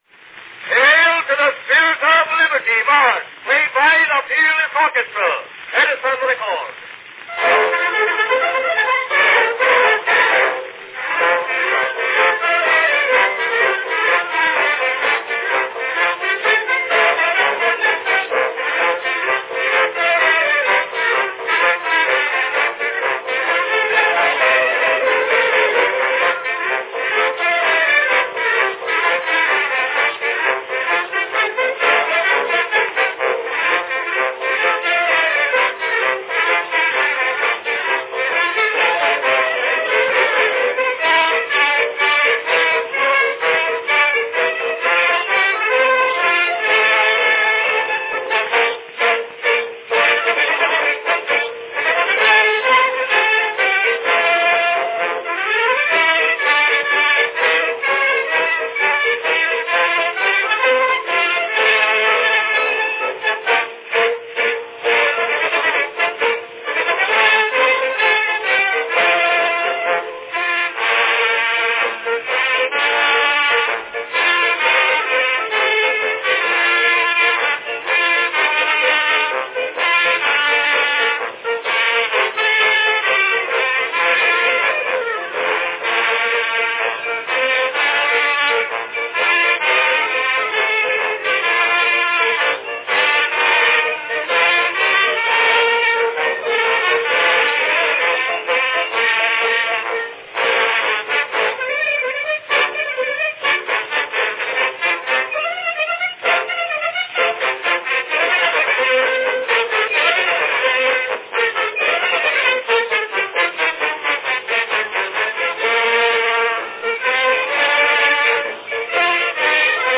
From 1901, John Philip Sousa's Hail to the Spirit of Liberty, performed by the Peerless Orchestra.
Category Orchestra
Performed by Peerless Orchestra
Announcement "Hail to the Spirit of Liberty March, played by the Peerless Orchestra.  Edison record."
It carries the brash sound, typical of this period, of a cylinder copied pantographically from a master record.